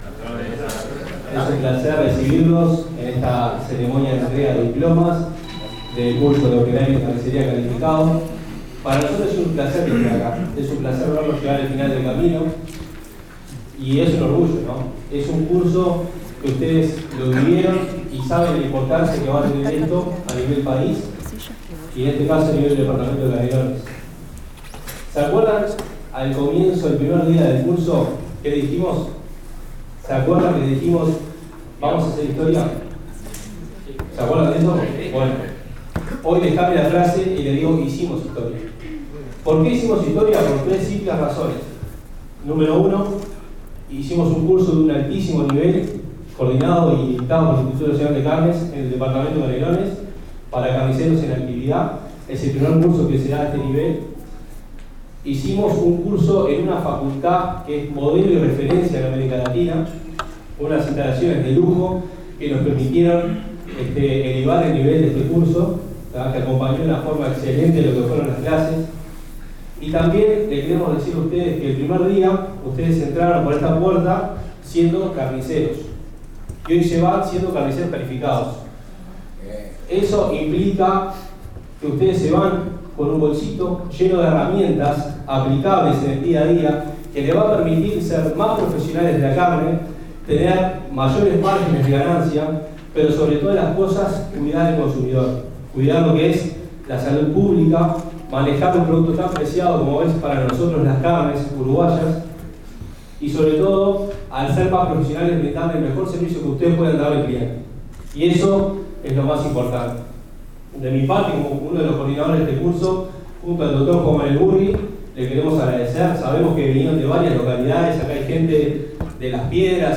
Escuchar el AUDIO del acto de entrega de diplomas.